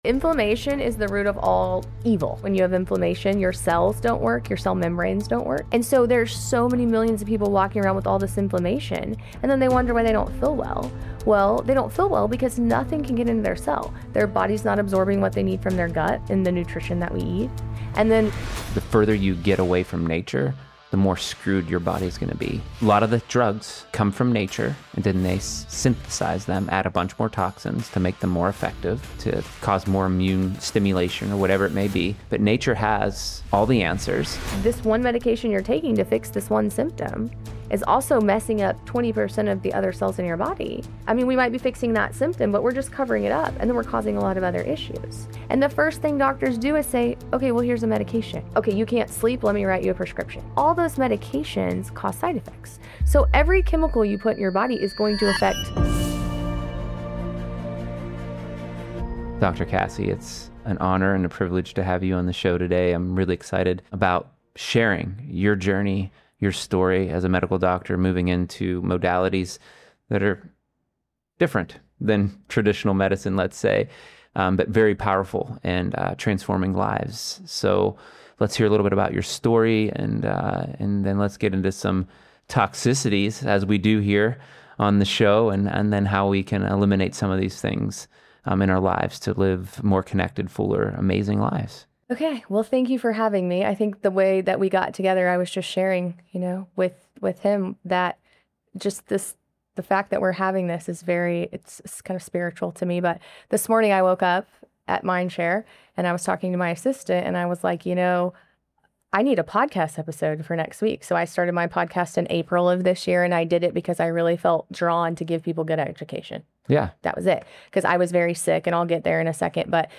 In this inspiring conversation about finding true healing